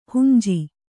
♪ hunji